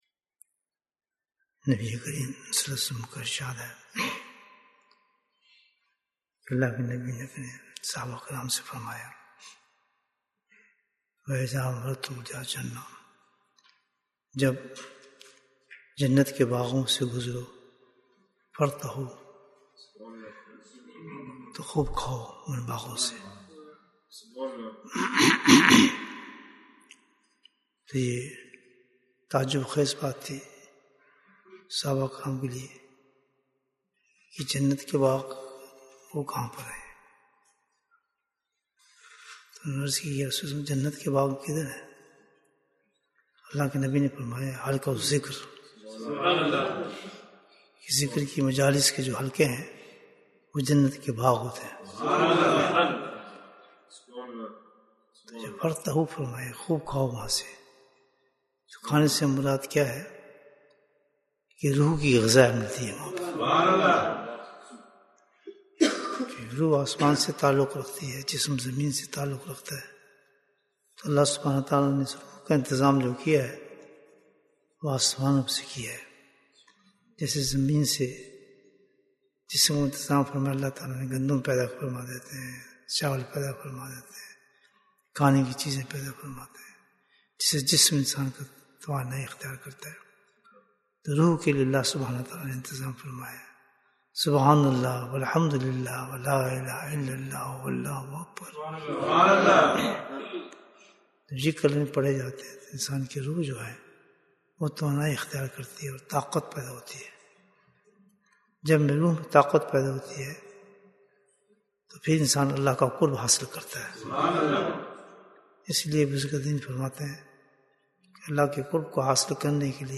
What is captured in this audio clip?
Bayan, 8 minutes